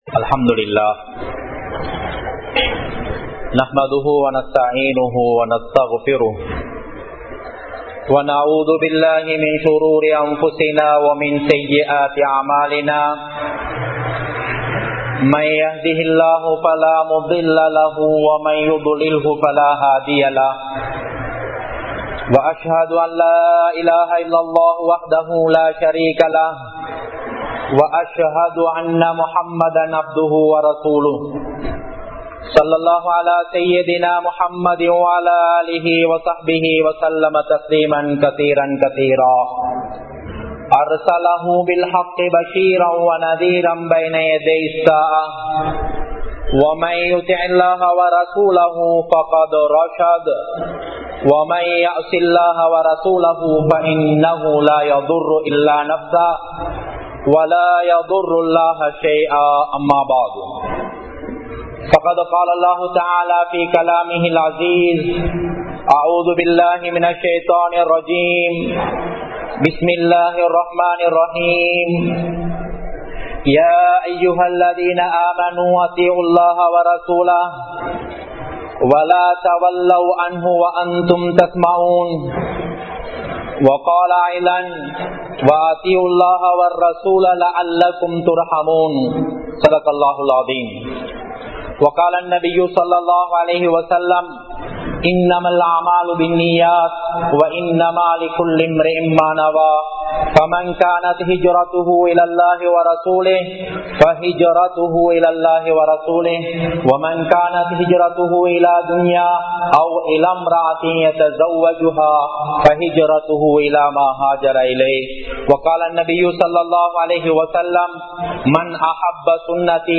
Nabi(SAW)Avarhalin Sunnaththin Perumathi (நபி(ஸல்)அவர்களின் ஸூன்னத்தின் பெறுமதி) | Audio Bayans | All Ceylon Muslim Youth Community | Addalaichenai